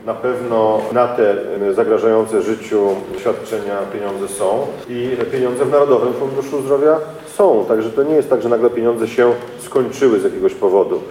Minister zapewnia, że w sytuacjach zagrażającym życiu, zabiegi będą wykonywane.